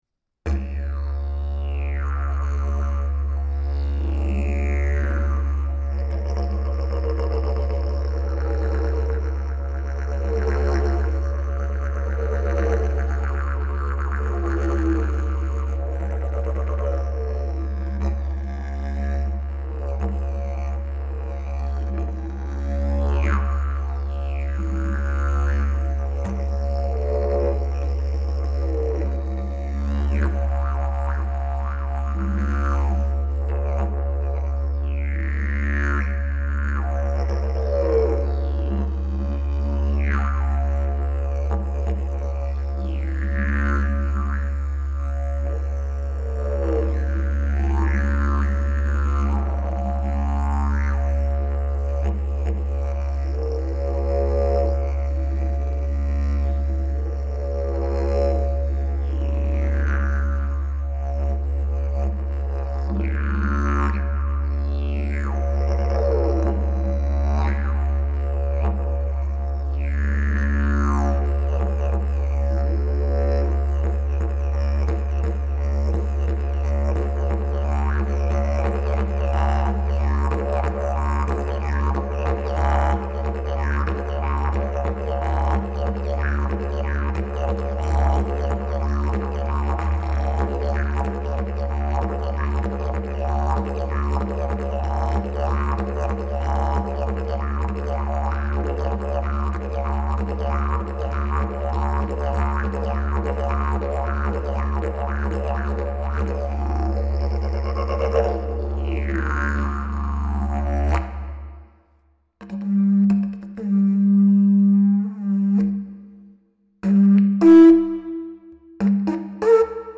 Key: C# Length: 54" Bell: 4" Mouthpiece: Red Zebrawood, Granadillo Back pressure: Very strong Weight: 3 lbs Skill level: Any
Didgeridoo #636 Key: C#